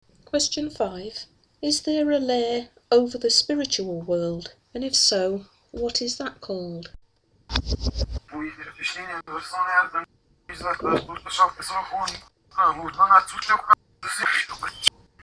Here I am asking if there is a layer over the spiritual realm, and if so, what is it called? At the beginning of the response there is a strange noise and different Technicians are heard speaking to each other about a problem.
A second Technician speaks and says ‘I would not switch over’, a Third voice then speaks and realises I can hear them by saying ‘There’s a human’, they then cut transmission.